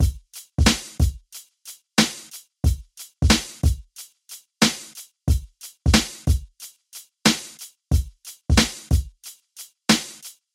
乐活节拍
描述：简单的LoFi嘻哈鼓
Tag: 91 bpm Hip Hop Loops Drum Loops 1.77 MB wav Key : Unknown